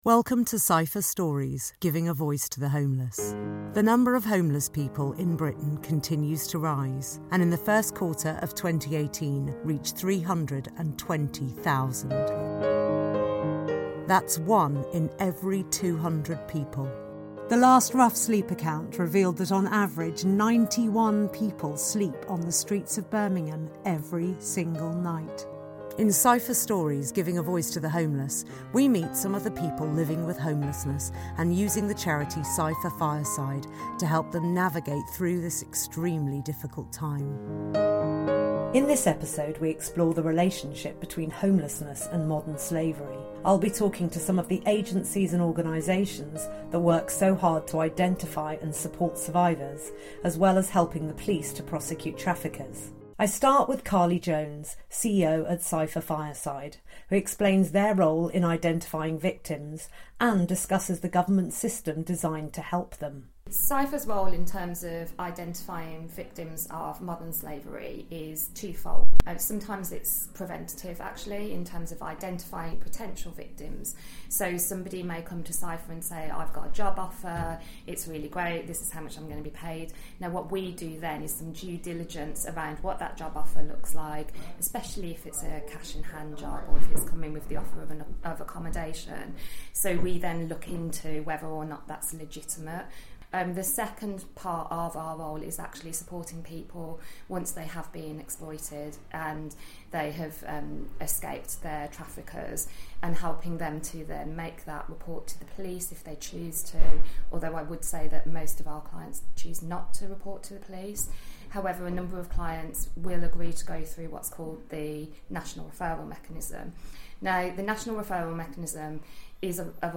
In this episode we explore the relationship between homelessness and modern slavery. We talk to those at forefront of victim support and to a survivor who is trying to rebuild his life after being trafficked